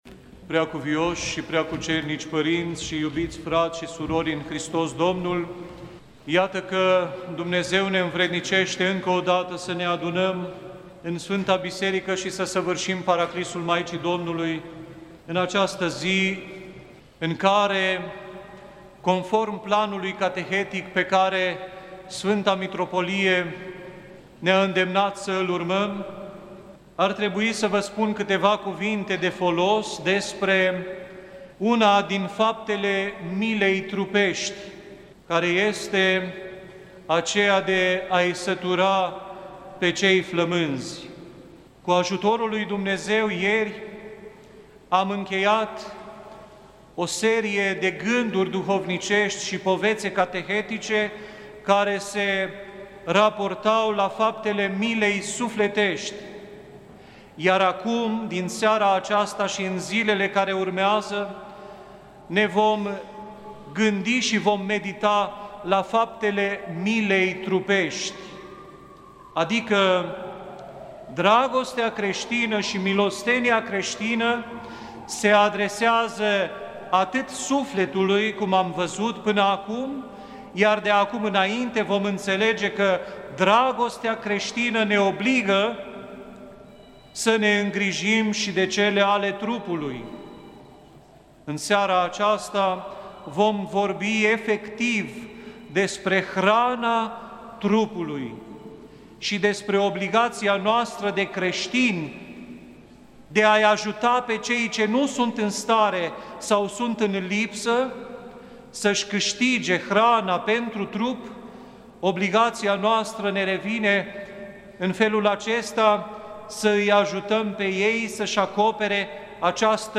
A sătura pe cei flămânzi – cateheză
rostit marti seara, 8 august 2023, în Catedrala Mitropolitană din Cluj-Napoca.